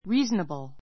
reasonable ríːznəbl リ ー ズ ナ ブる 形容詞 道理をわきまえた, 分別のある; 道理にかなった; 適当な, （値段が） 手頃 てごろ な I can't do it, Mike.—Be reasonable.